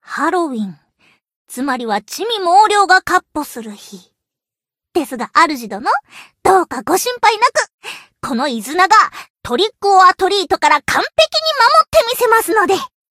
贡献 ） 分类:蔚蓝档案 分类:蔚蓝档案语音 协议:Copyright 您不可以覆盖此文件。